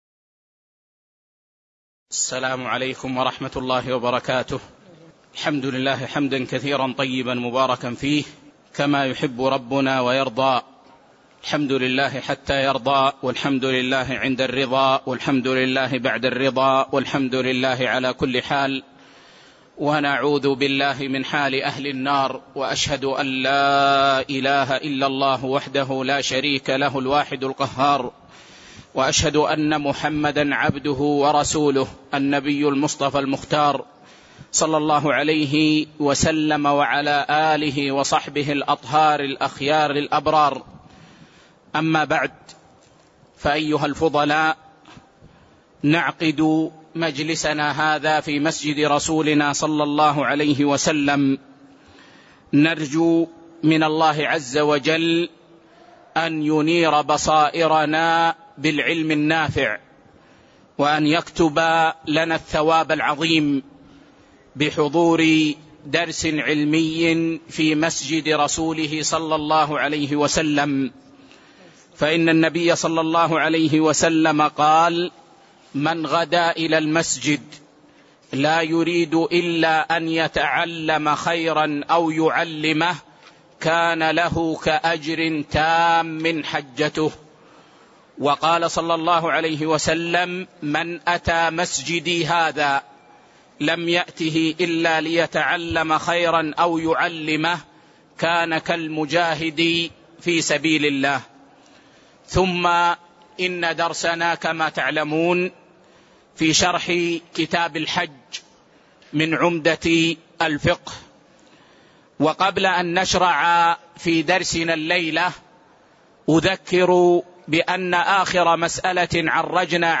تاريخ النشر ٥ ذو الحجة ١٤٣٤ هـ المكان: المسجد النبوي الشيخ